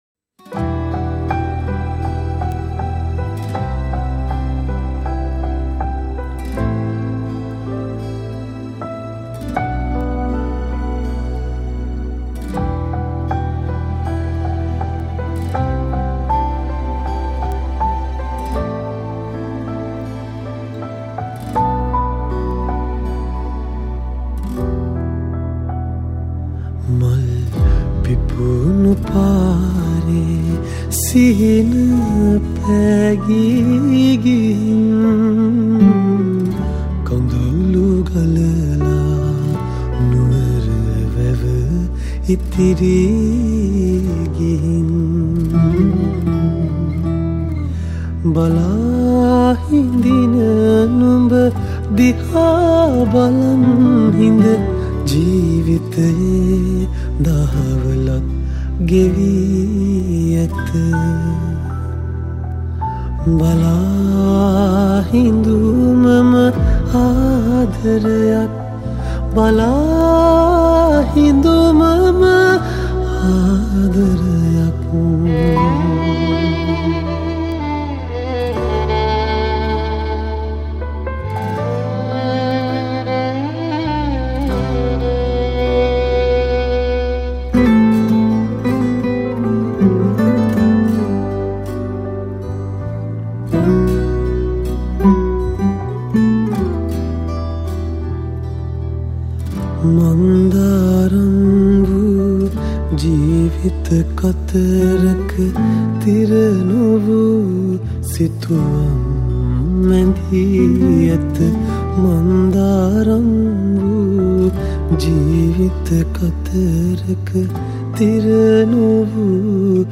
All Keys& Track Programming
Guitars
Violin